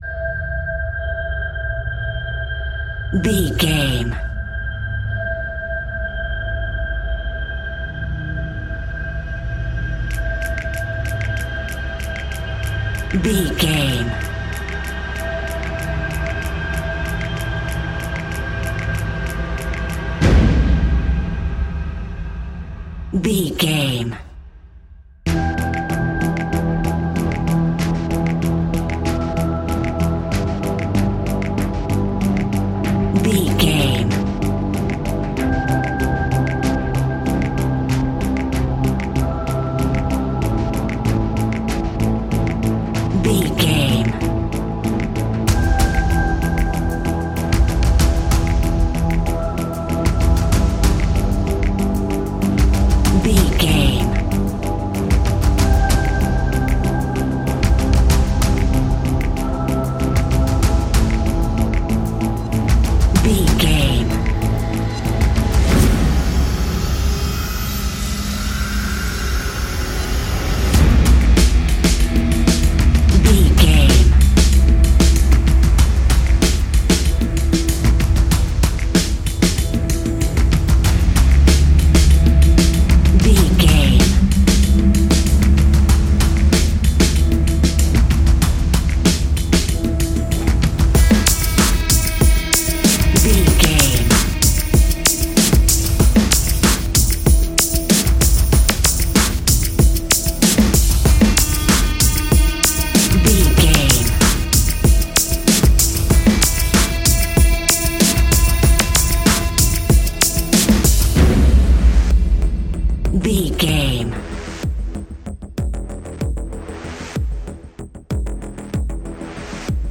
Fast paced
In-crescendo
Ionian/Major
dark ambient
EBM
drone
synths
instrumentals